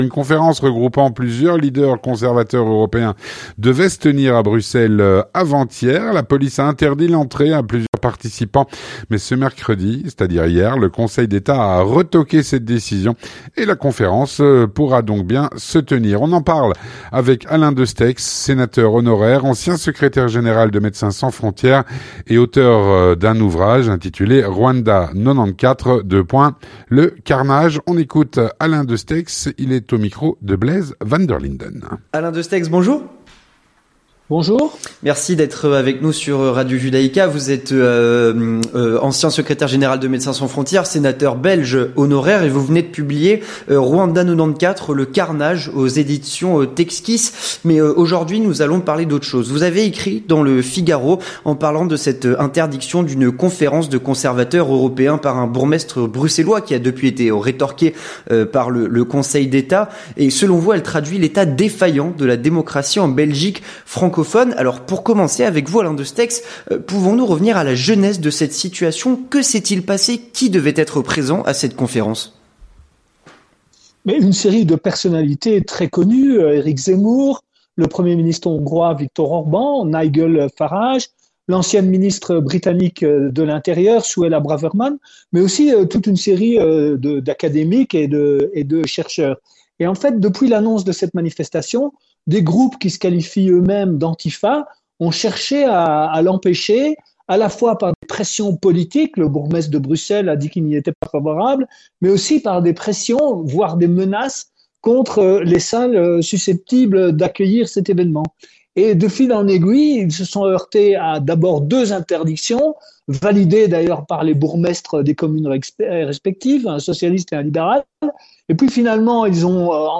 L'entretien du 18H - Une conférence regroupant plusieurs leaders conservateurs européens devait se tenir à Bruxelles ce mardi.
Avec Alain Destexhe, sénateur honoraire, ancien secrétaire général de MSF et auteur de "Rwanda 94 : Le carnage"